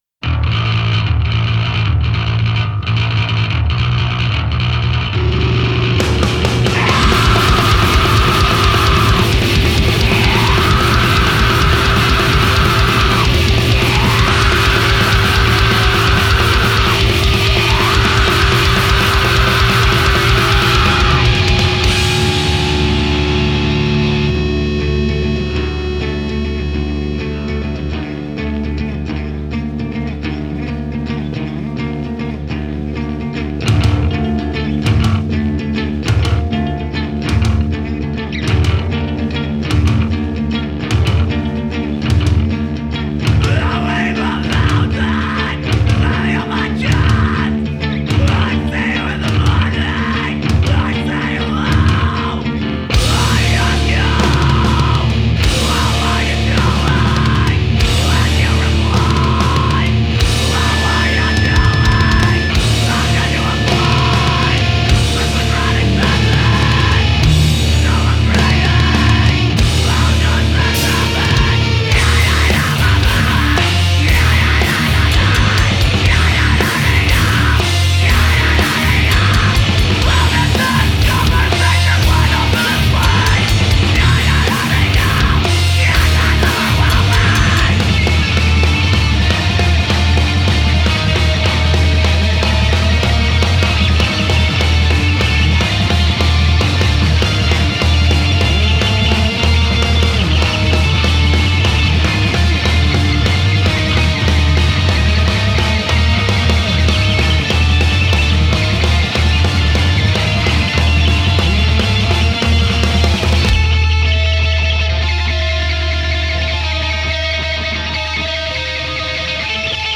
emo See all items with this value
Punk Rock Music